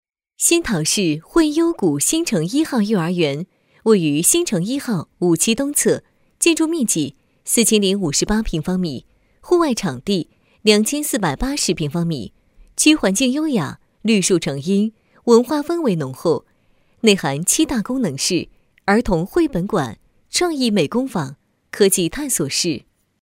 女3号